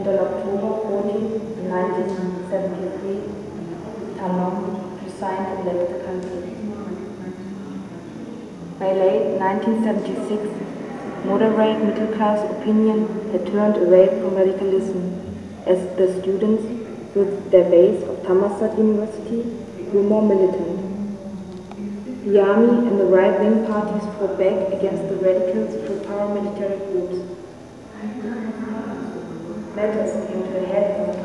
I Am, She Is, We Are- Working On Fire based on 13 interviews, researched and realised in exchange with the Manoa Free University, dedicated to the global and local movements of informal and precarious workers 31 miniatures of working and living rooms a submultiple of the "global city" as embodied factory. 13 women tell a story about their everyday life, their desires and working conditions.